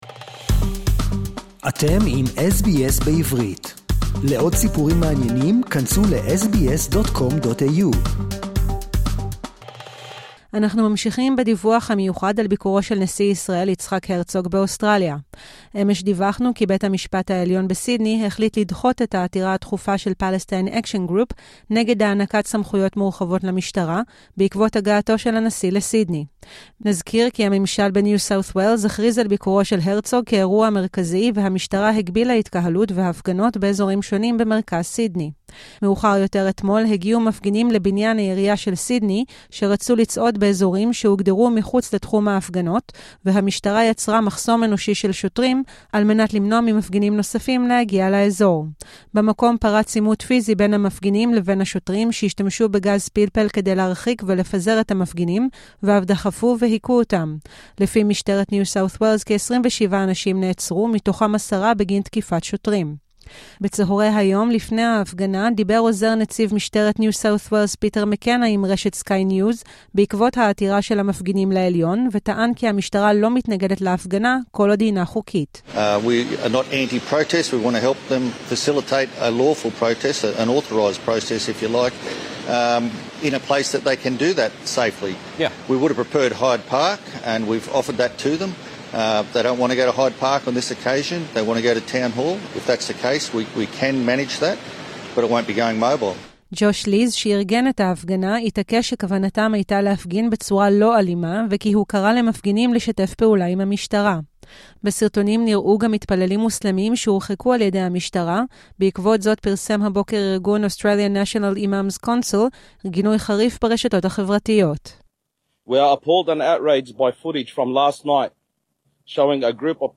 דיווח מיוחד - ביקור נשיא ישראל יצחק הרצוג באוסטרליה - יום 2